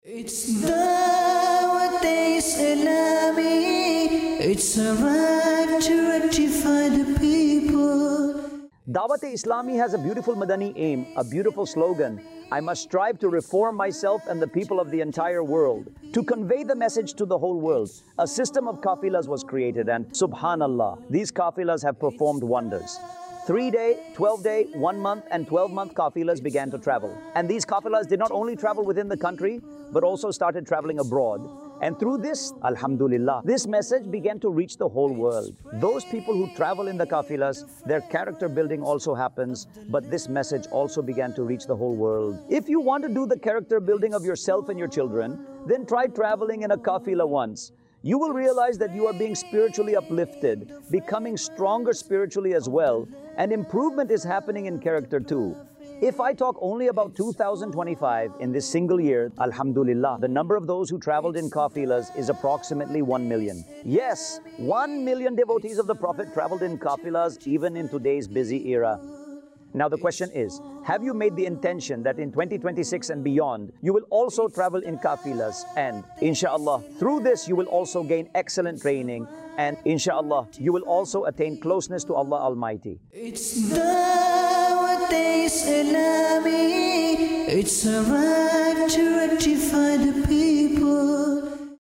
Qafilah | Department of Dawateislami | Documentary 2026 | AI Generated Audio
قافلہ | شعبہِ دعوت اسلامی | ڈاکیومینٹری 2026 | اے آئی جنریٹڈ آڈیو